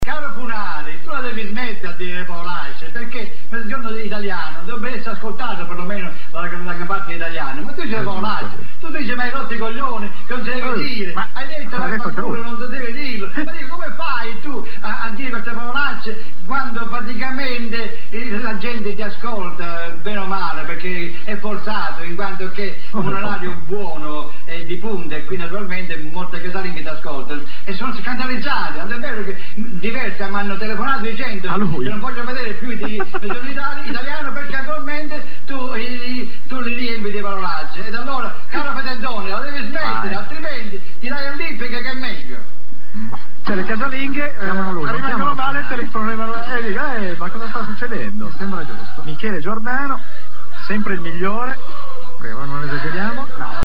Ma ecco alcuni mp3 tratti da "Mai dire TV" della Gialappa's Band: